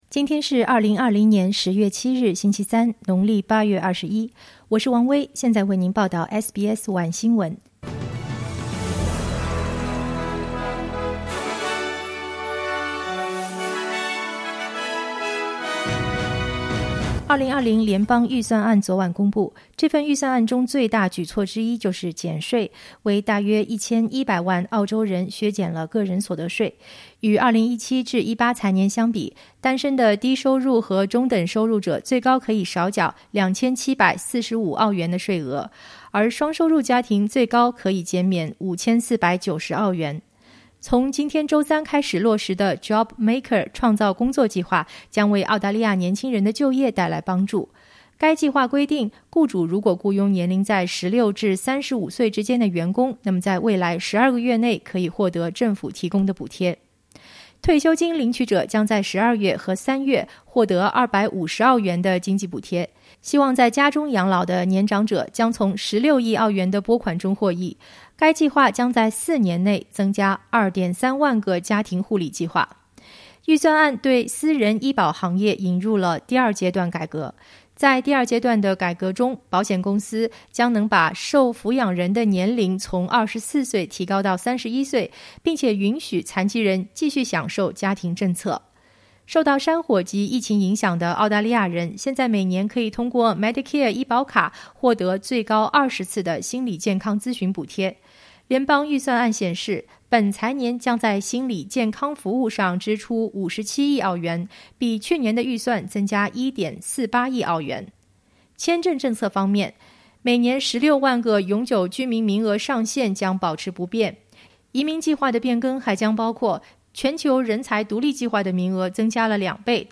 SBS晚新闻（10月7日）